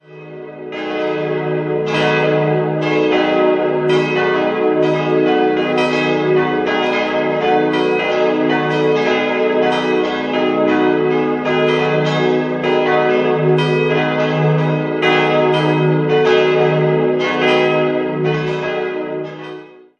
Idealquartett: dis'-fis'-gis'-h' Die beiden kleinen Glocken wurden 1911 von Karl Hamm in Regensburg gegossen.
Seit 1984 komplettiert die große Glocke aus Karlsruhe das Geläut.